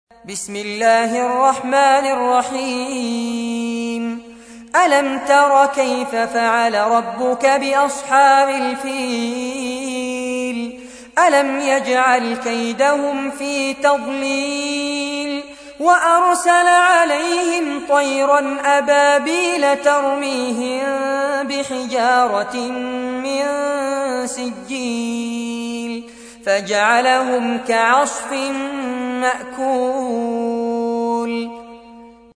تحميل : 105. سورة الفيل / القارئ فارس عباد / القرآن الكريم / موقع يا حسين